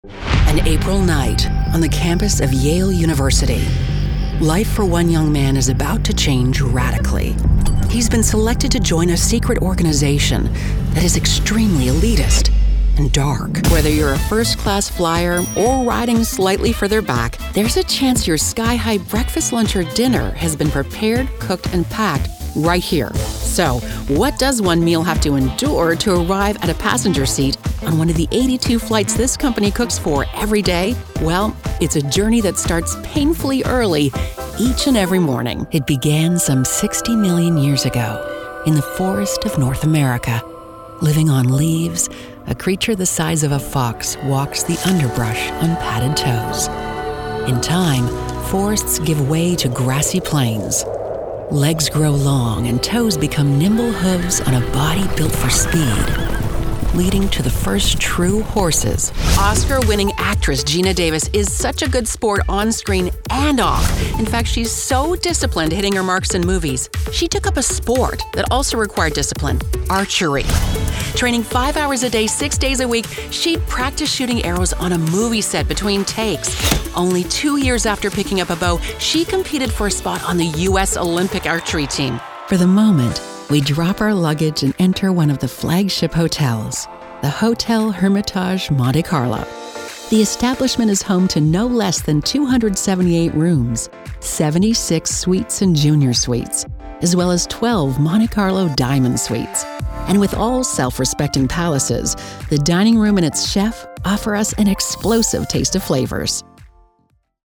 In-Show Narration / Documentary Demo
English - USA and Canada
Middle Aged